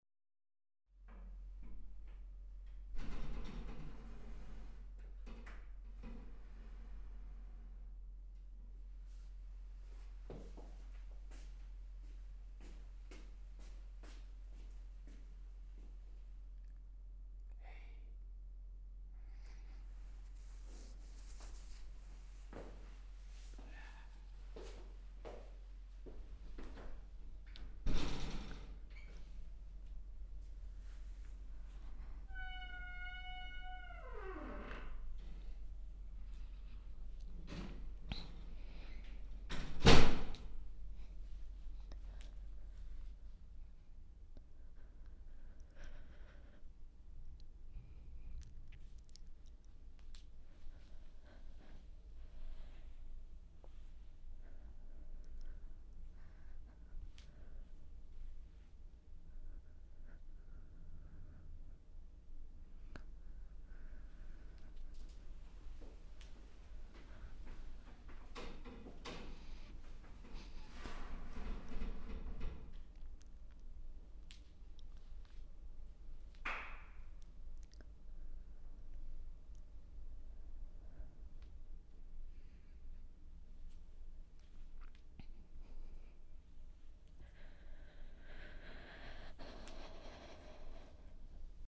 Il consiglio è sempre lo stesso: ASCOLTATE SOLO CON LE CUFFIE altrimenti non potrete cogliere gli effetti spaziali
Passi
passi.mp3